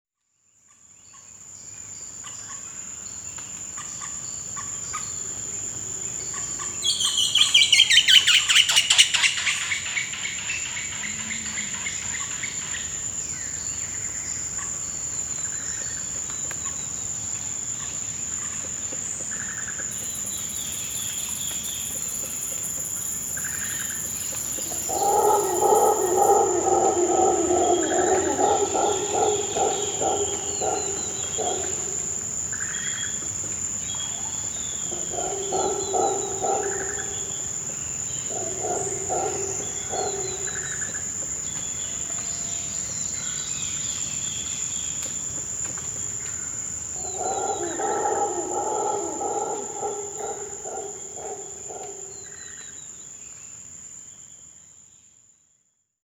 „Sempervirent“ is a stereo edit of a series of ’sequence-shots‘ where the quadraphonic microphone setup records all that passes by.
02 at dawn among the river – birds and monkeys – 8’15
at_dawn_cano_negro_wildlife_refuge_excerpt.mp3